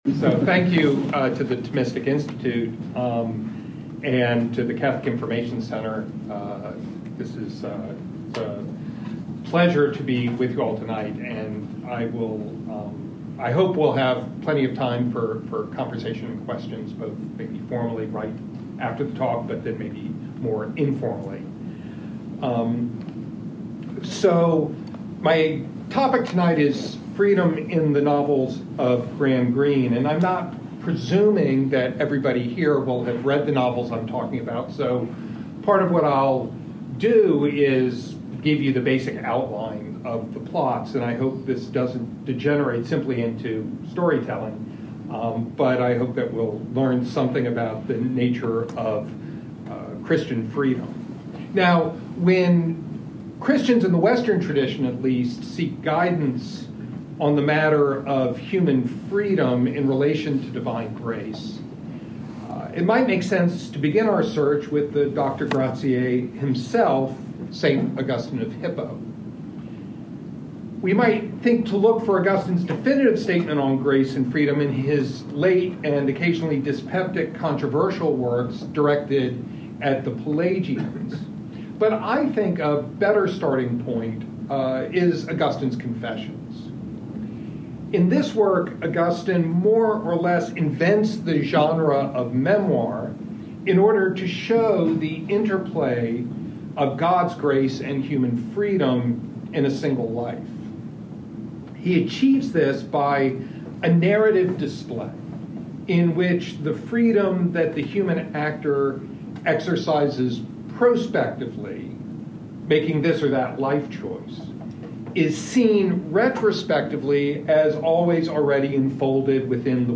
This lecture was given at the Catholic Information Center in Washington, D.C., on November 13, 2019.